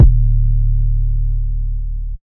OZ-808 (Huncho).wav